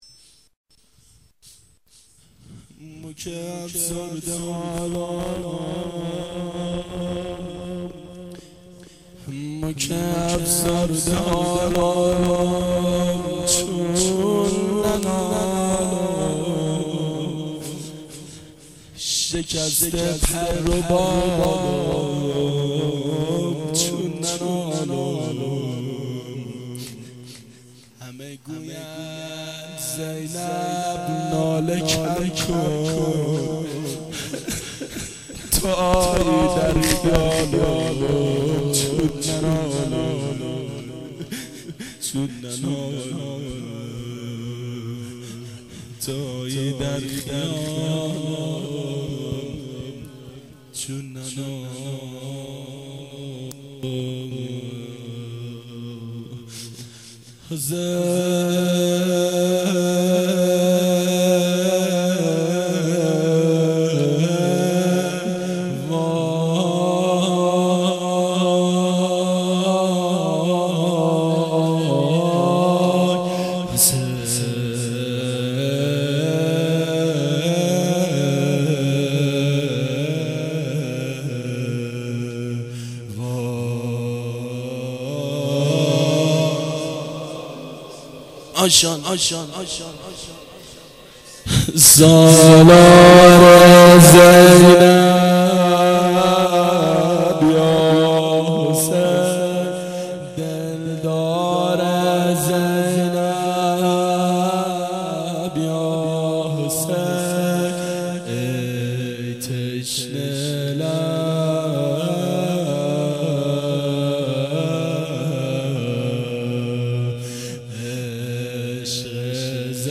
گلچین جلسات هفتگی سال 1387